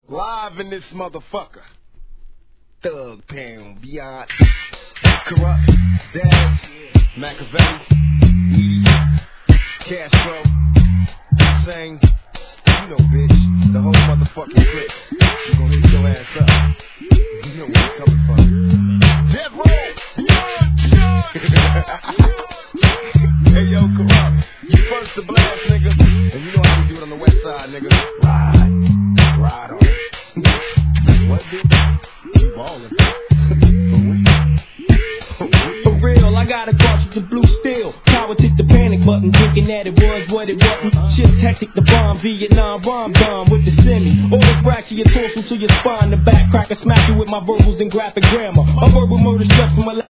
12inch
G-RAP/WEST COAST/SOUTH